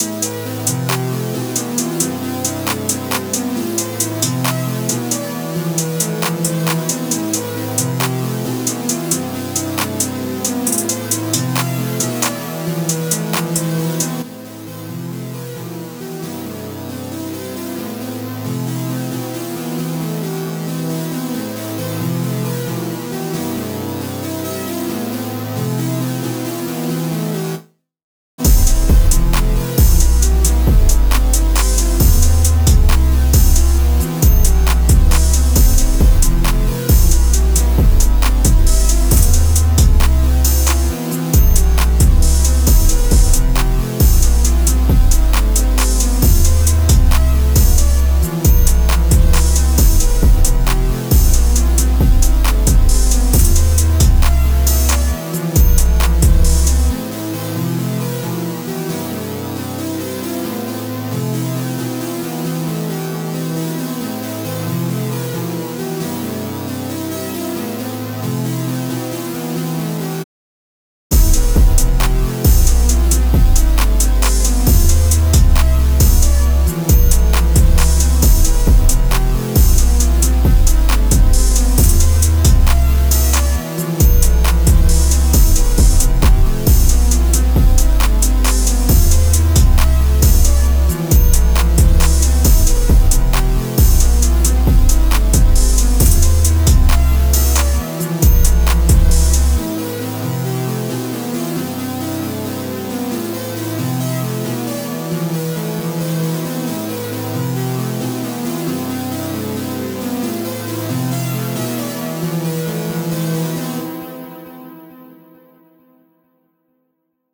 Жанр: Trap, эксперментал, гранж, эмбиент